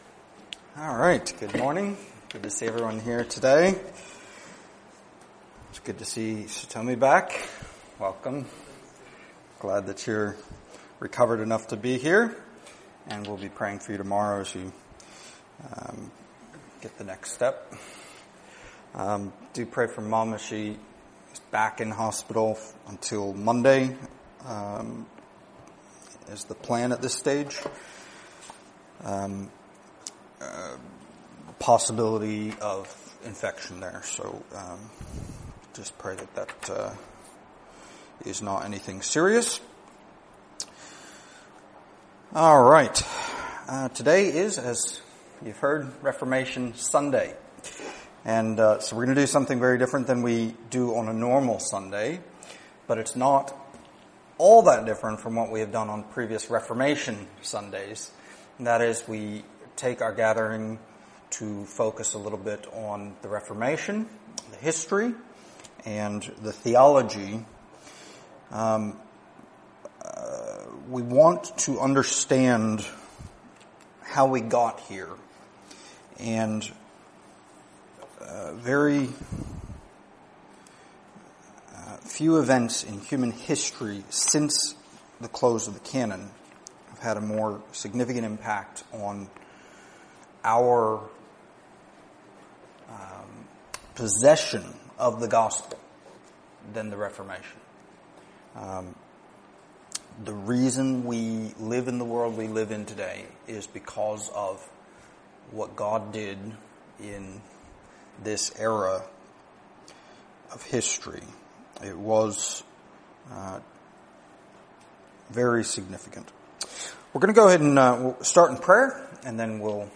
Series: Stand Alone Sermons